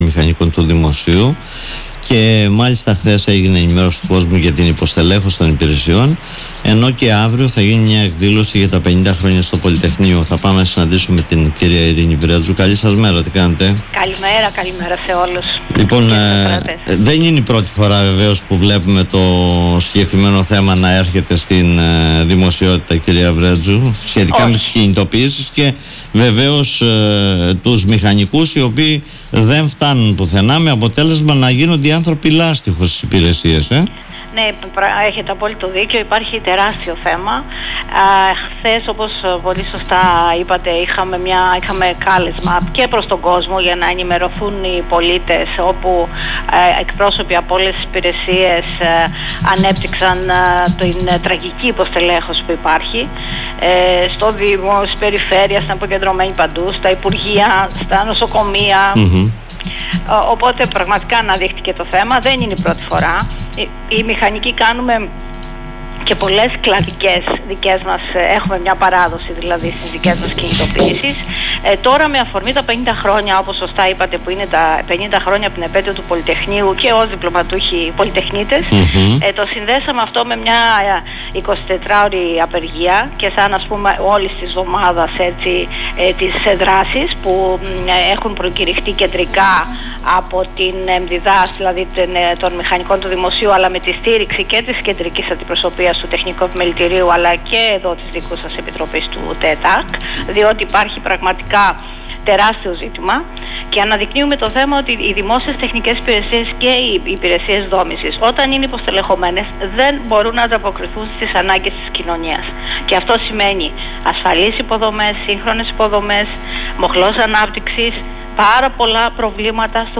που μίλησε στον politica 89.8